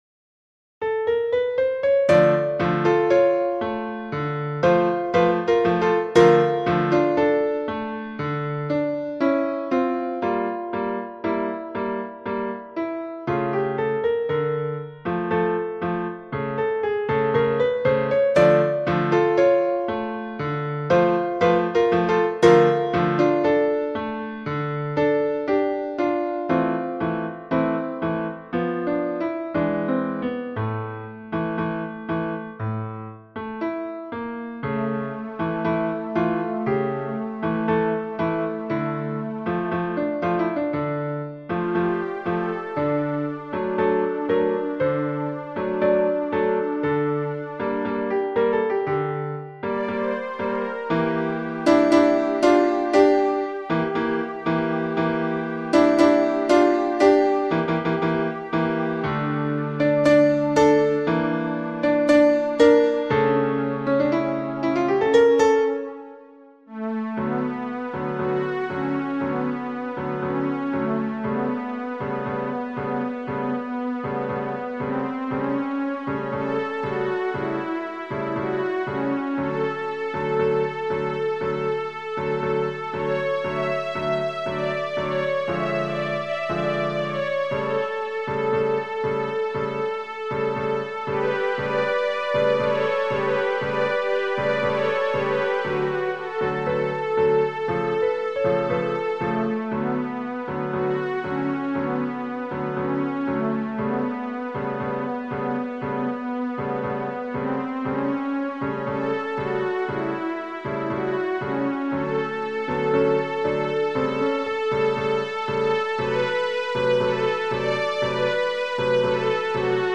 Genere: Ballabili
tango